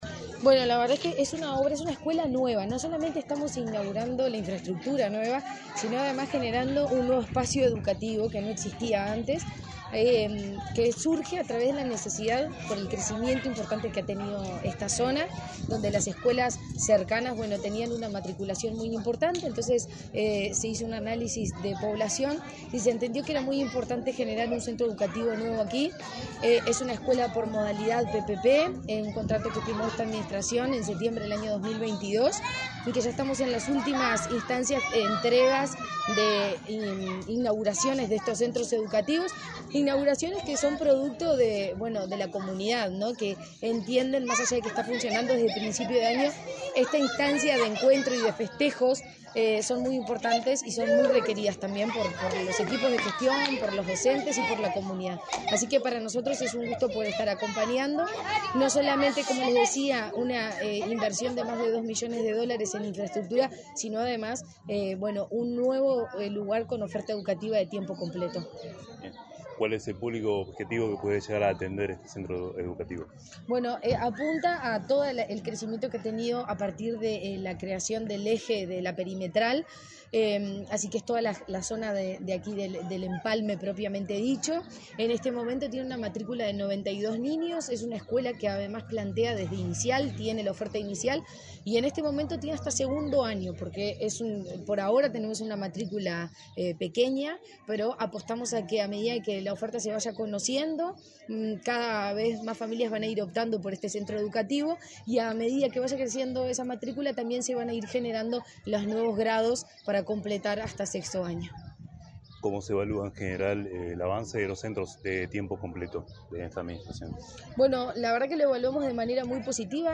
Entrevista a la presidenta de ANEP, Virginia Cáceres